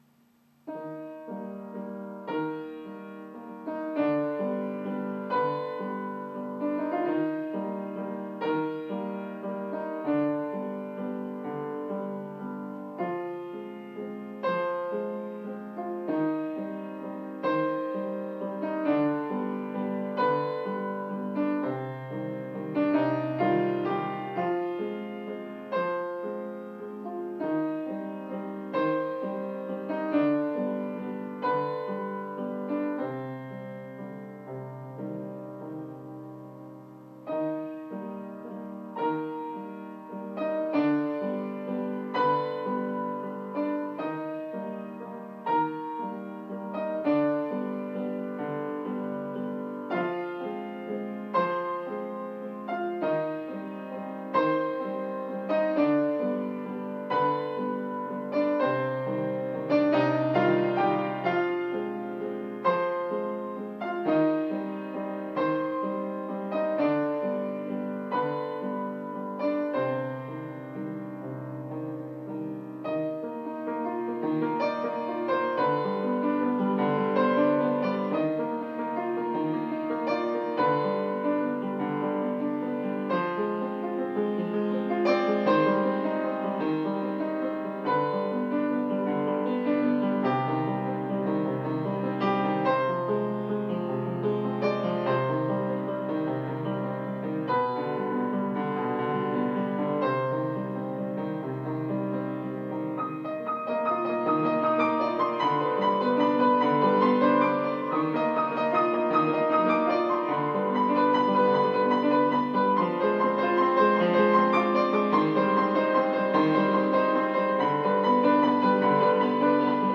RAS, sinon vachement déconcentré pcq enregisté